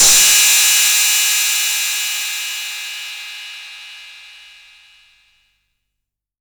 808CY_1_TapeSat.wav